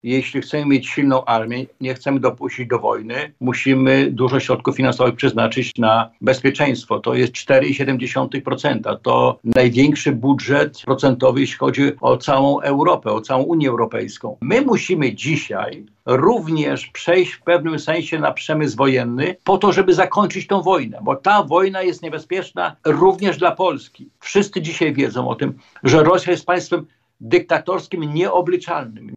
Według Krzysztofa Grabczuka, posła Koalicji Obywatelskiej, który był gościem porannej rozmowy w Radiu Lublin, skala wydatków na armię to efekt rosyjskiej agresji na Ukrainę.